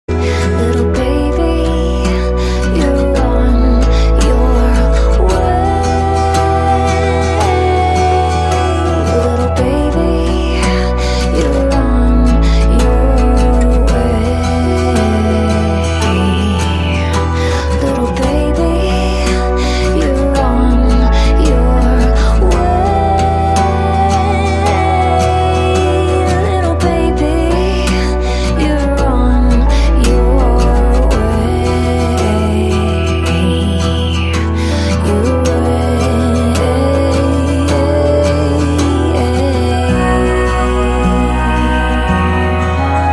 M4R铃声, MP3铃声, 欧美歌曲 114 首发日期：2018-05-14 12:06 星期一